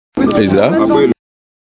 Vernissage de l’exposition des collectifs Eza Possibles et OKUP